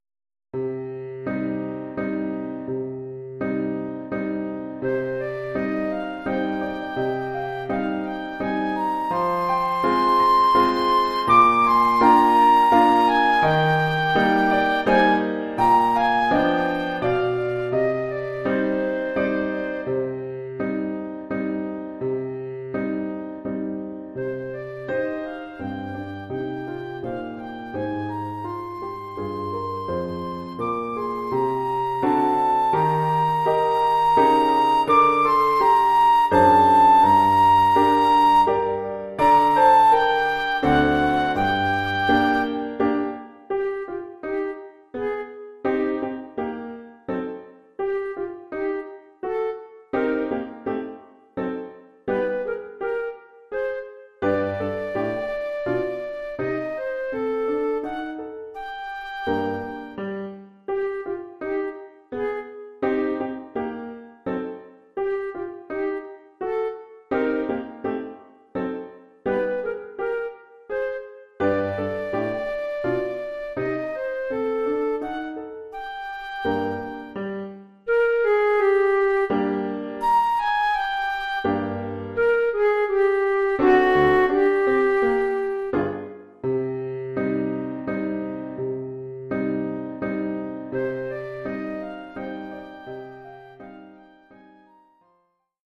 Oeuvre pour flûte et piano.